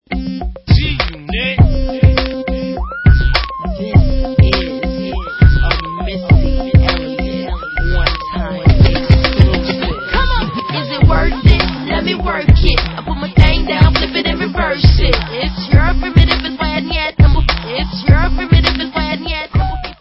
sledovat novinky v oddělení Dance/Hip Hop